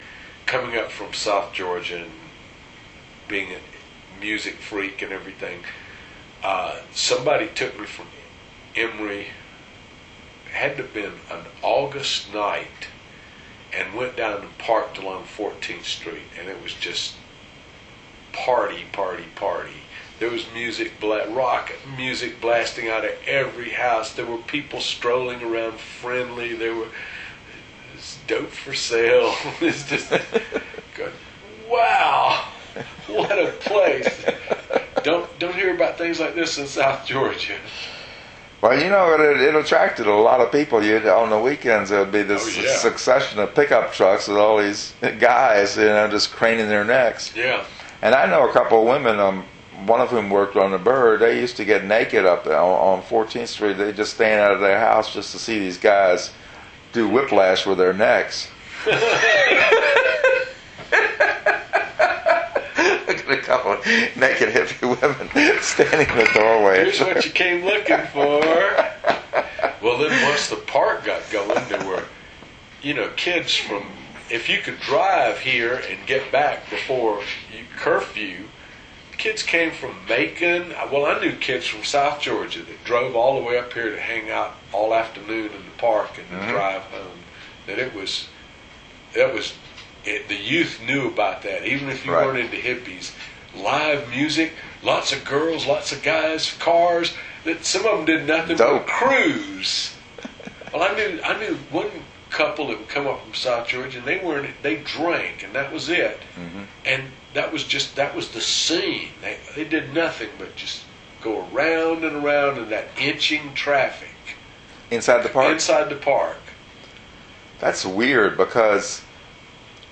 Bird People, interview